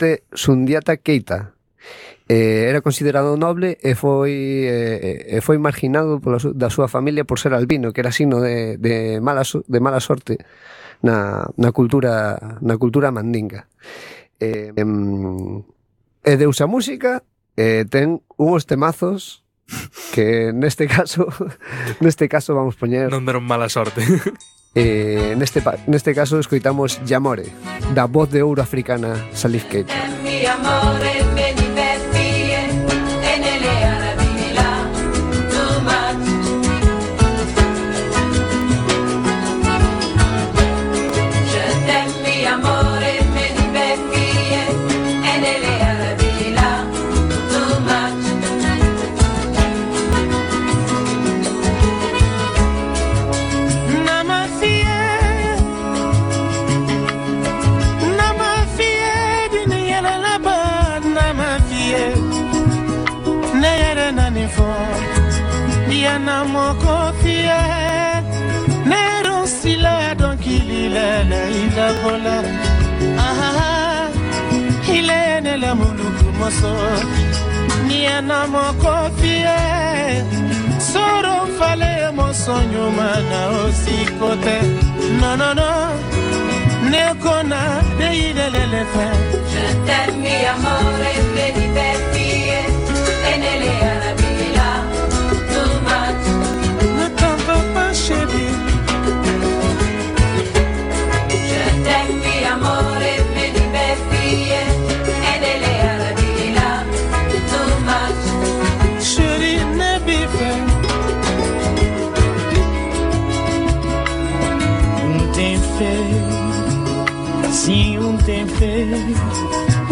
Conexión en directo con Radio Pardiñas, a emisión radiofónica desde o Festival de Pardiñas (Pardiñas, Guitiriz, Lugo).